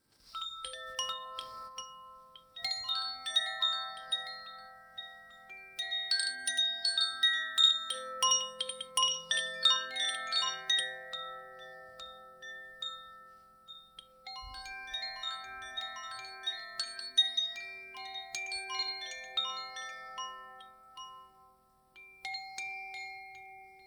Koshi Wind chime / Musical Instrument. Terra - (Earth)
Eight chords are welded with silver into the metal plate at the base of the resonance tube. Precise tuning creates a play of clear tones that is rich in overtones. The overtones of the shorter chords gradually dominate and become fundamentals, thus forming a circular tone range.
Move the chime gently holding it by its cord: the crystalline relaxing sound may leave you in quiet wonder.
Koshi-Wind-Chime-Terra.wav